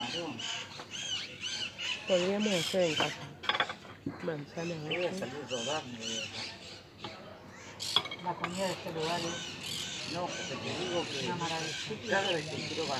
Blue-crowned Parakeet (Thectocercus acuticaudatus)
Location or protected area: Rincon Del Socorro
Condition: Wild
Certainty: Recorded vocal
Calancate-cabeza-azul_1.mp3